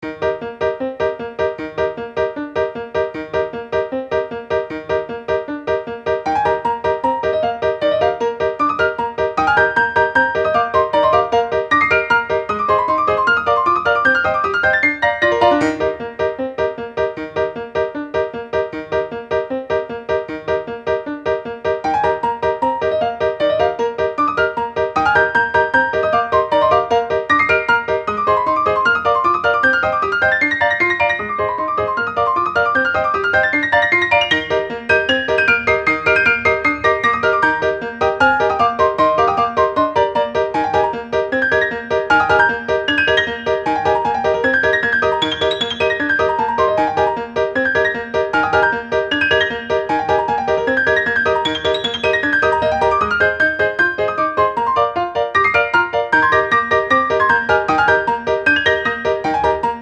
ogg(L) - 疾走 にぎやか コミカル